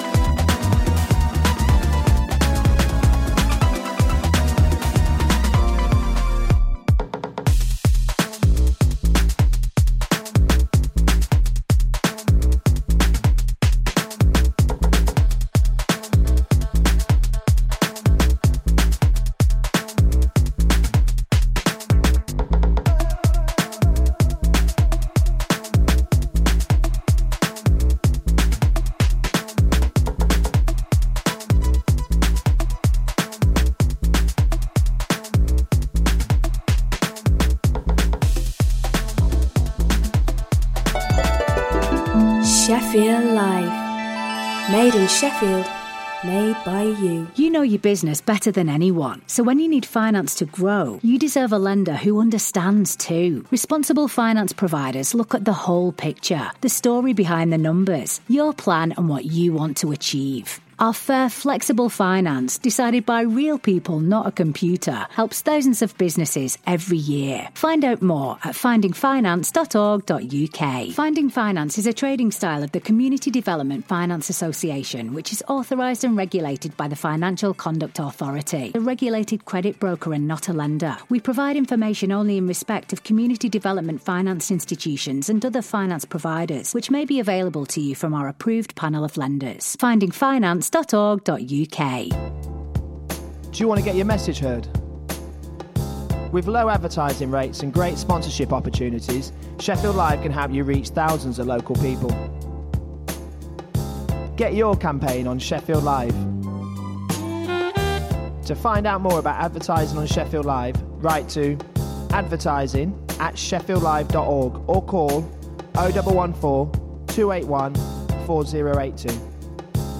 Roastin some leftfield dance type music!